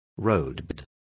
Complete with pronunciation of the translation of roadbed.